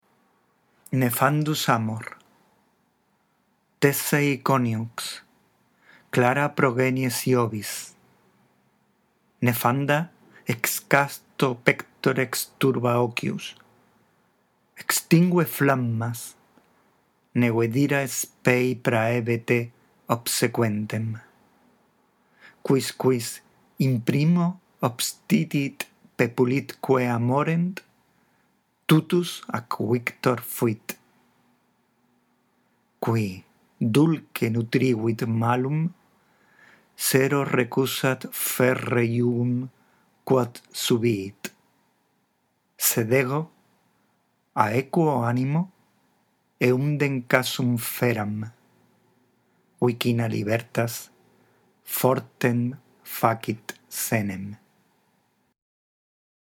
La audición de esta grabación te ayudará a practicar la lectura en latín